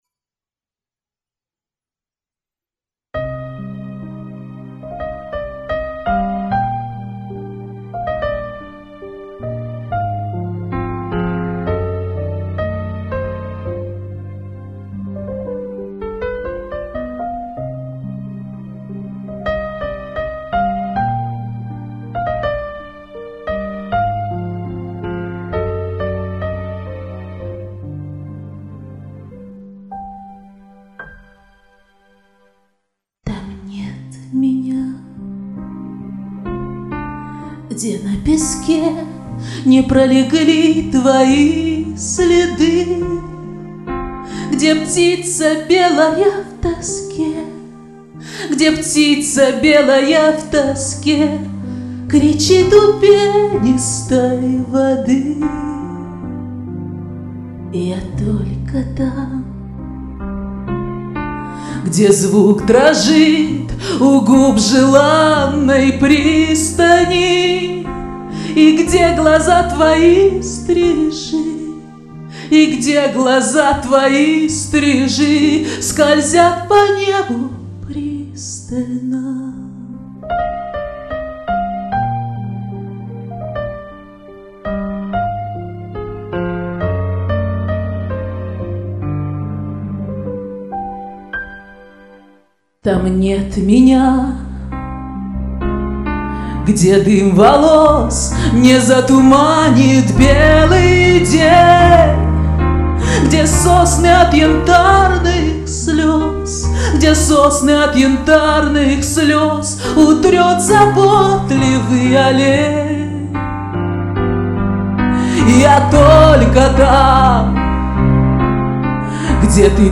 СПЕЛА С ДУШОЙ И НЕЖНОСТЬЮ.